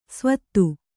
♪ svādu